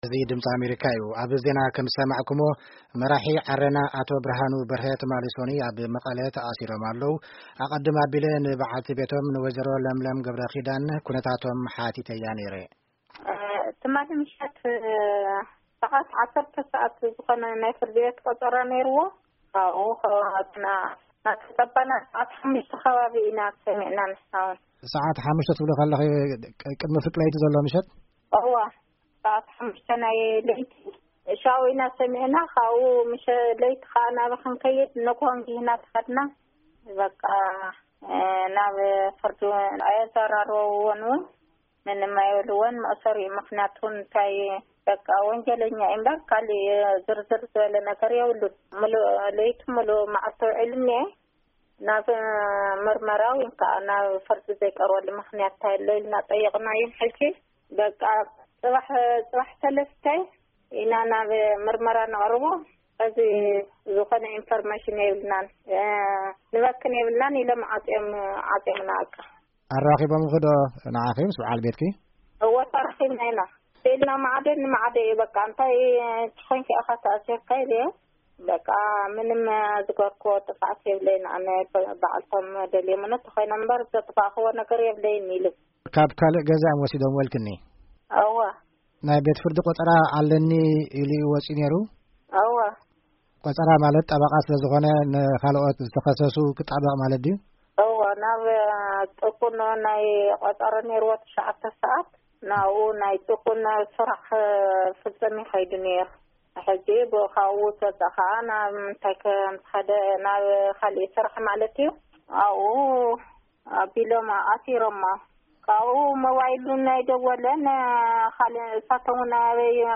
ቃለ-መጠይቕ